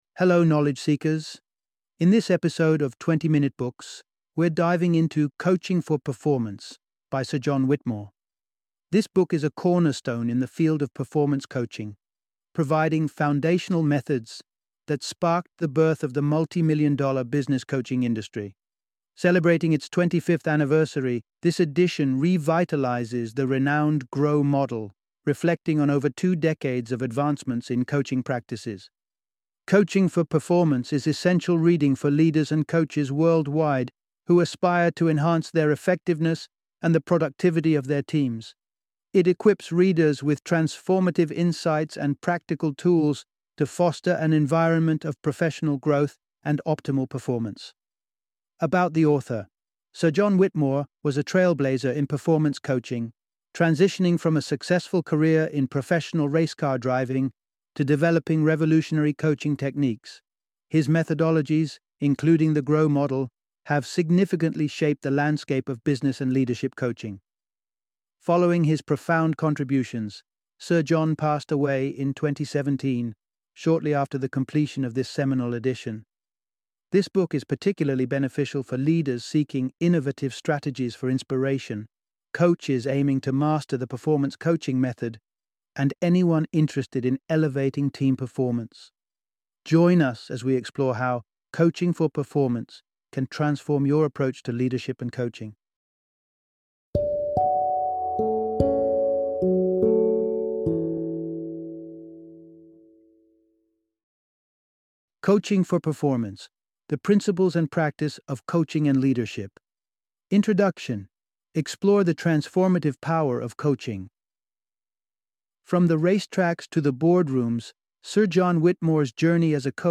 Coaching for Performance - Audiobook Summary